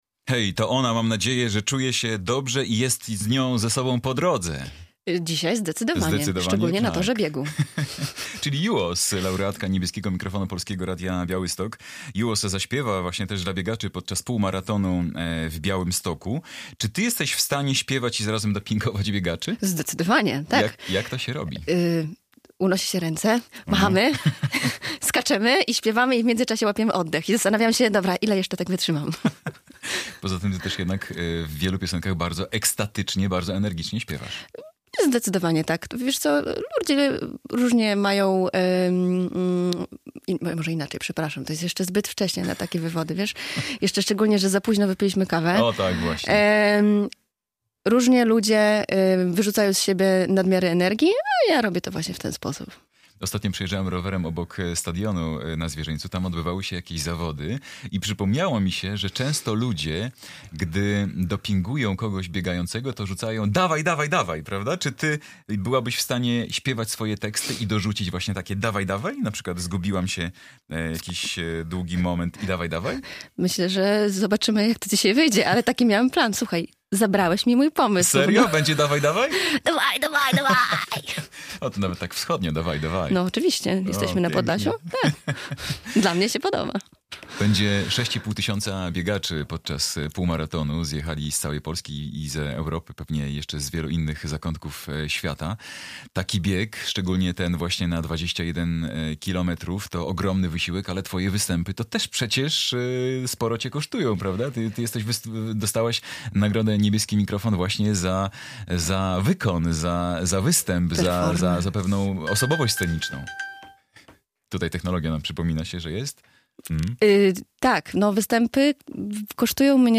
wokalistka, laureatka konkursu Niebieski Mikrofon Polskiego Radia Białystok